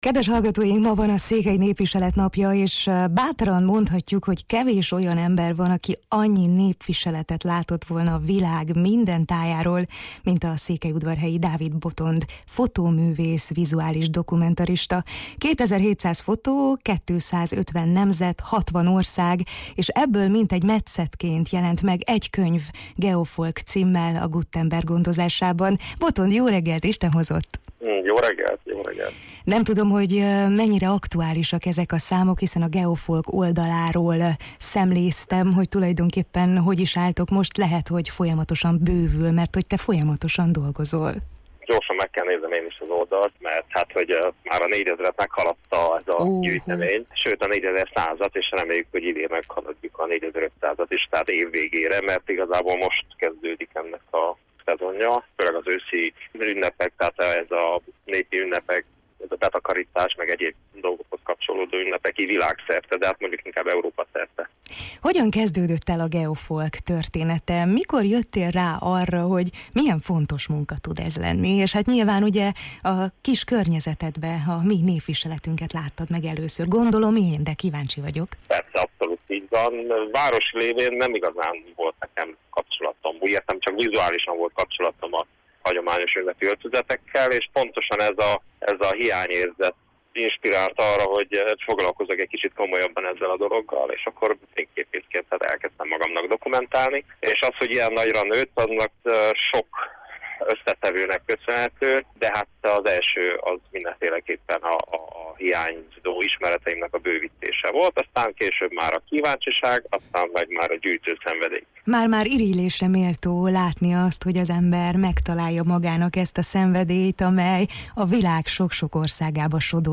A Jó reggelt, Erdély!-ben mesélt a Geofolk indulásáról és küldetéséről, a viseletek mögötti emberi történetekről, valamint arról, miért fontos ma is a népviselet – nemcsak ünnepnapokon: